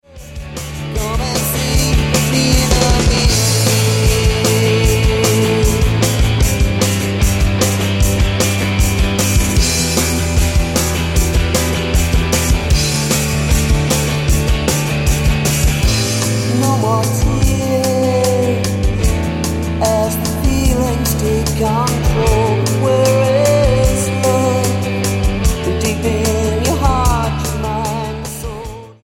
The melodic pop rock
Style: Rock